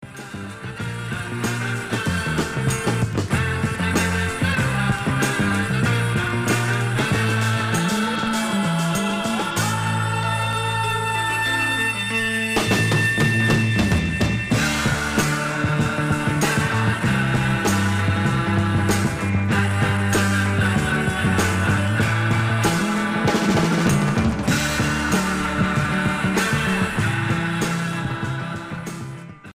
Il faut lui préférer celui-ci, le son étant supérieur.